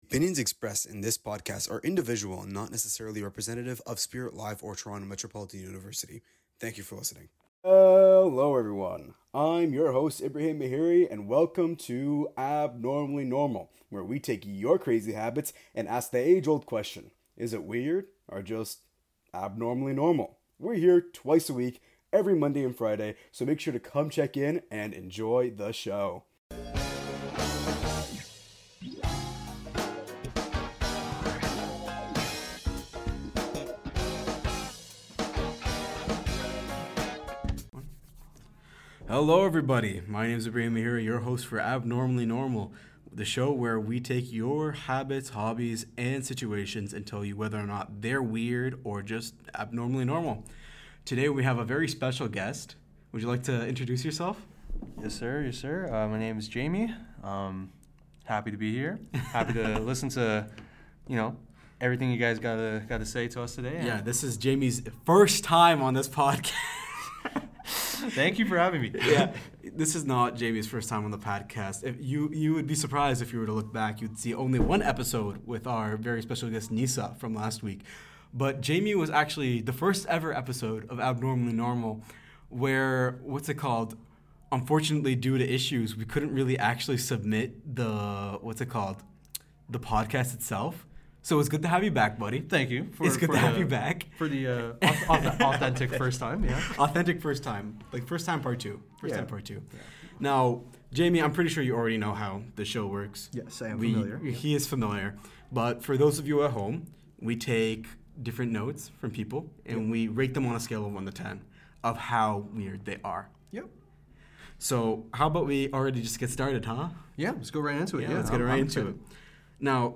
Each episode blends candid conversation with lighthearted debate, turning habits, hobbies, and odd routines into stories about what “normal” truly means. It’s funny, honest, and a little peculiar, a place where the unusual is usual, the serious is unserious, and most of all, where the abnormal is normal.